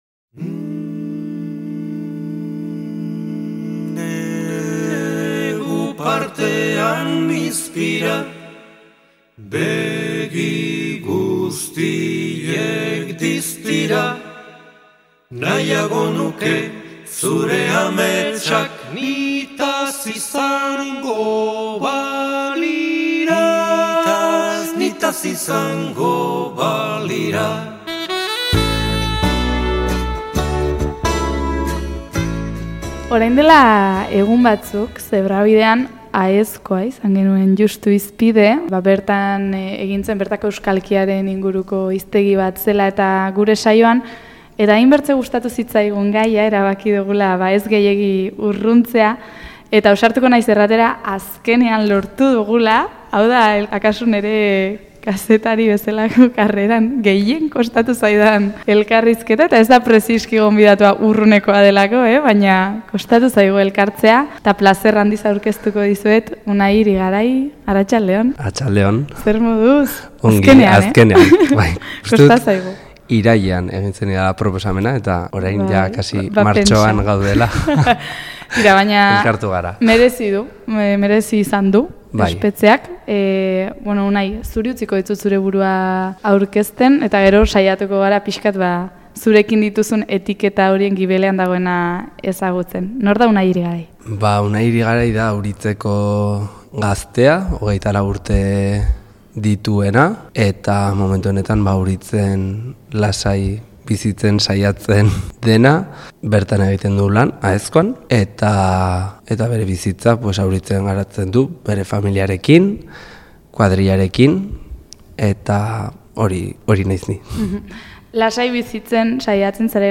Auriztarra da Unai Irigarai, eta bertan bizitzeko apustua egina du. Bertako alkatea ere bada, eta Pirinioetako herri batean bizitzeak dituen alde ilun eta argiei buruz aritu gara solasean.